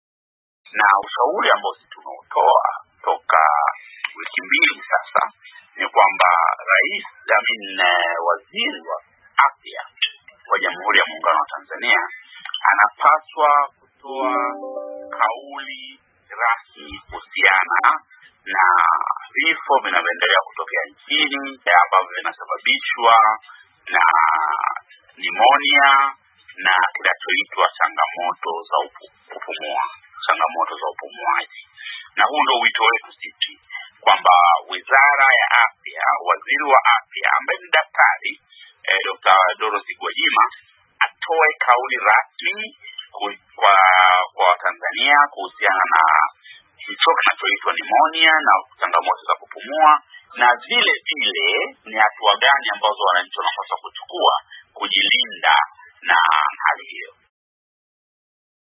COVID-19 : Maoni ya mwanasiasa Zitto Kabwe